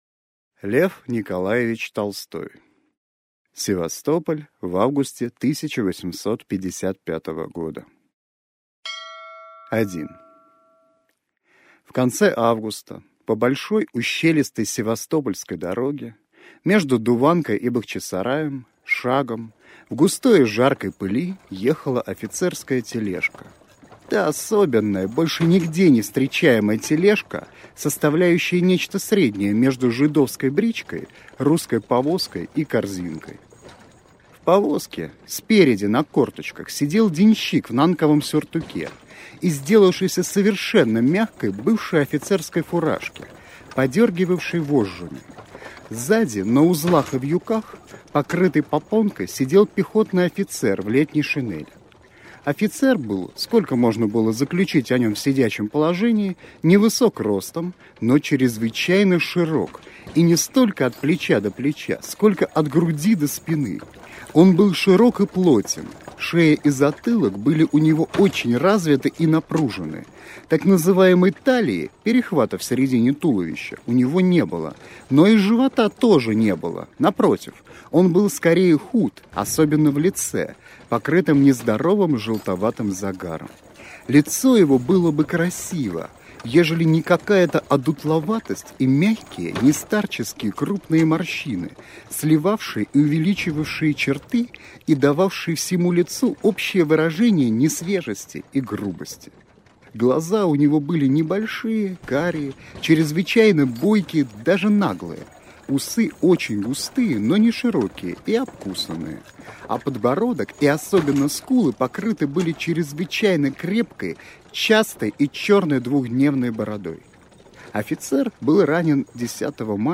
Аудиокнига Севастополь в августе 1855 года | Библиотека аудиокниг